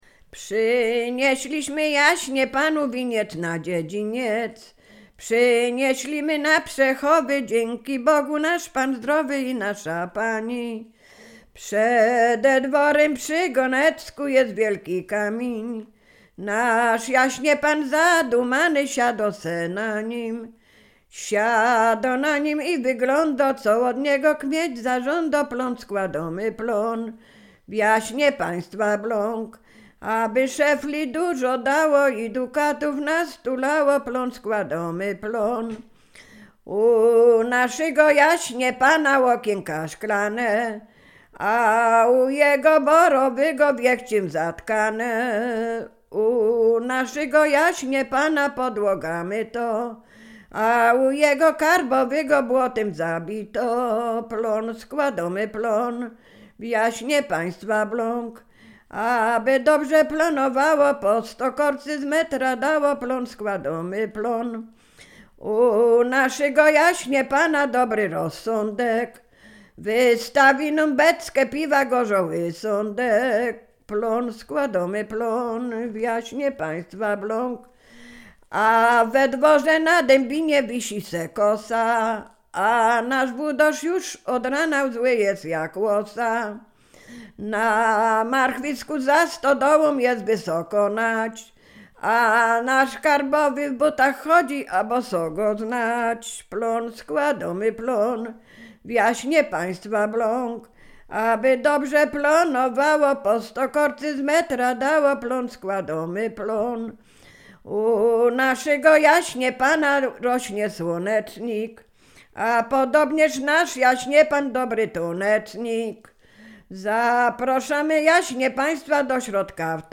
Sieradzkie
Dożynkowa
lato dożynki okrężne żniwne dożynkowe